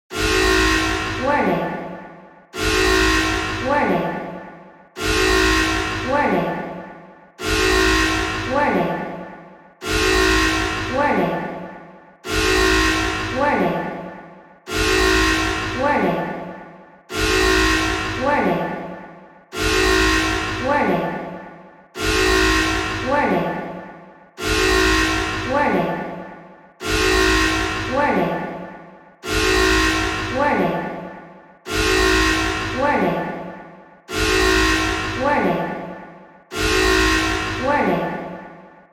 緊急事態や危険な状況をドラマティックに演出するための理想的な効果音です。